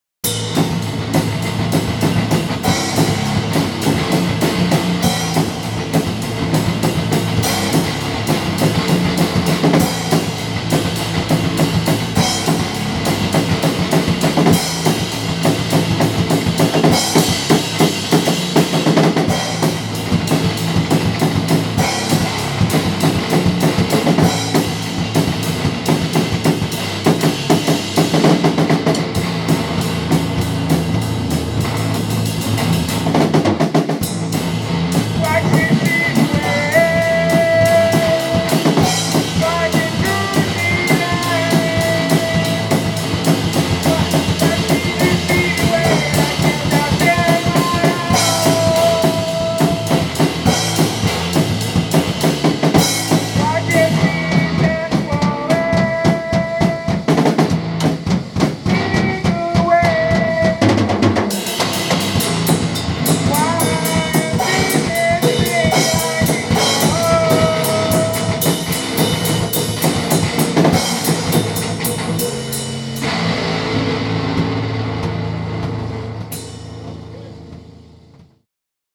where: Marsonic
Jam